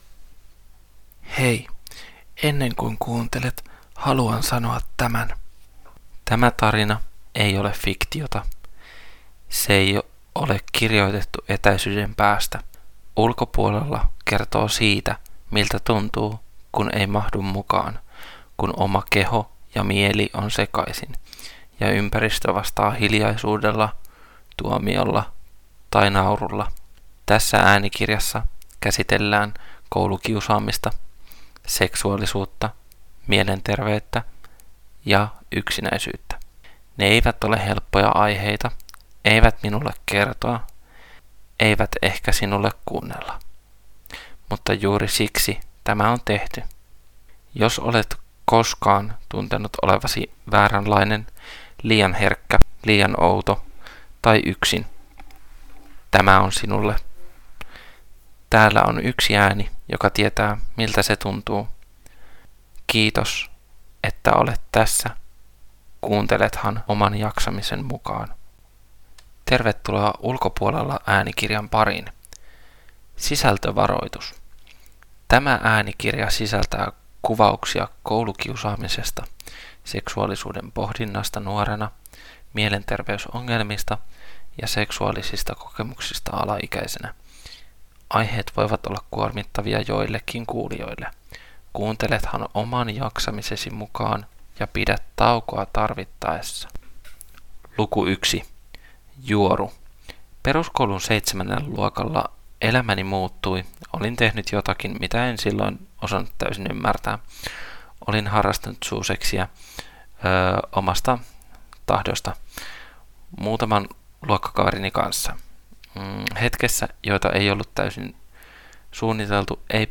Ulkopuolella äänikirja